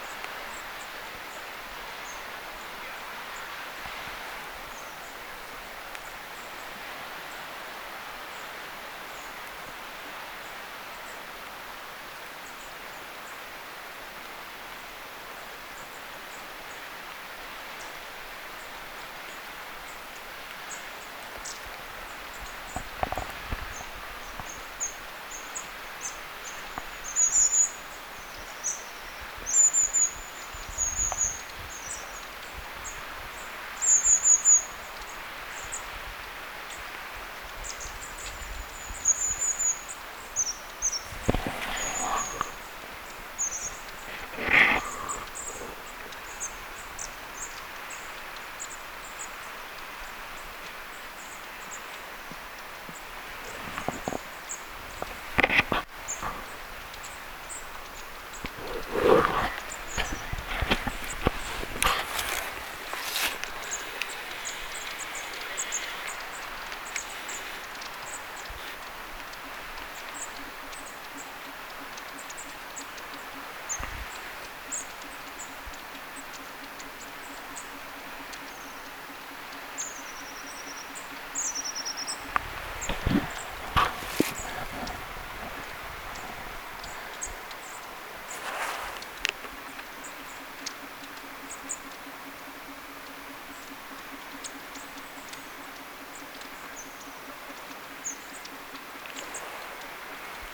pyrstötiaisia saaressa pieni parvi
pyrstotiaisia_saaressa_pieni_parvi.mp3